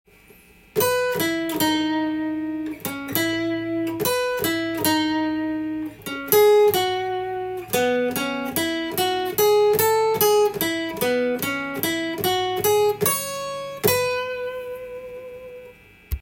譜面通りアコースティックギターで弾いてました